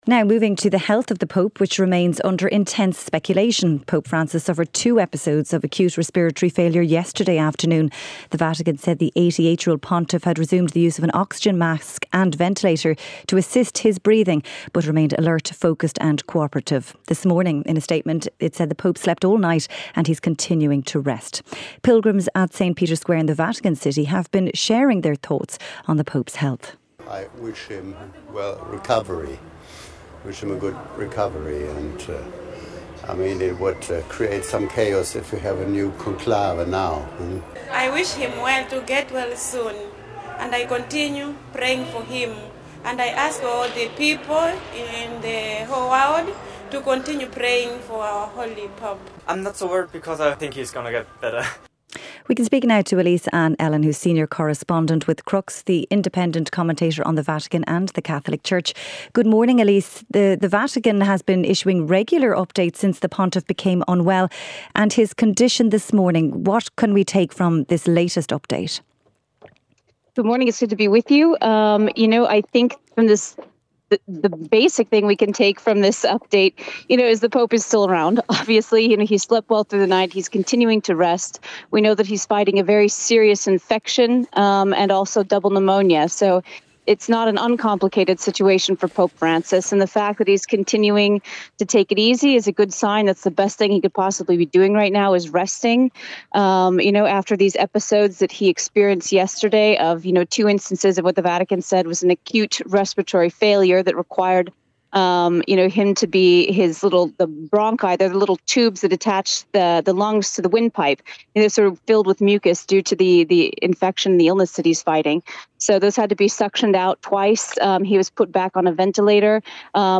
7:35am Sports News - 04.03.2025